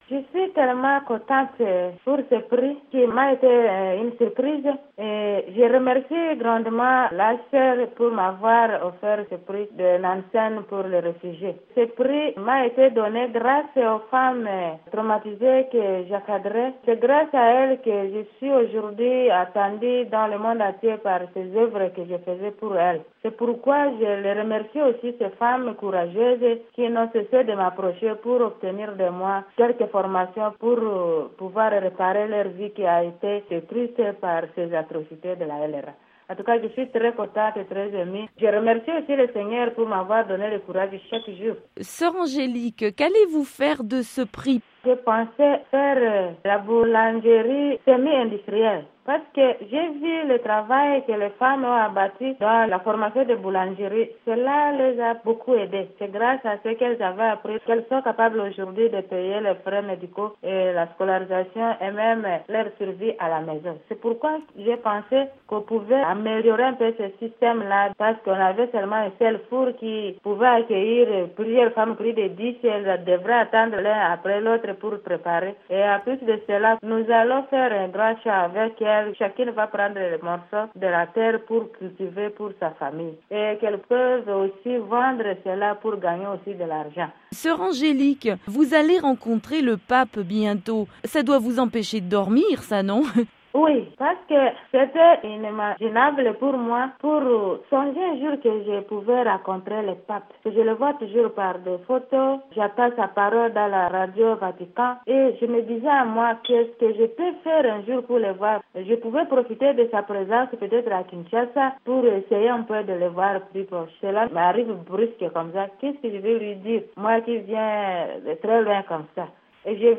Contactée à Dungu par la VOA, elle s'est dite «surprise» par la décision du HCR de lui décerner le Prix Nansen. Elle a exprimé sa gratitude aux femmes qu’elle assiste.